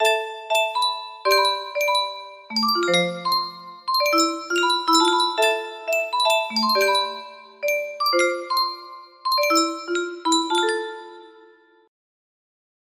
CAJITA MUSICAL music box melody